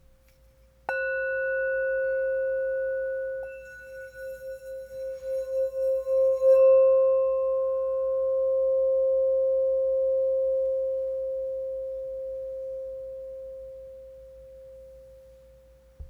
C Note 5.5″ Singing Bowl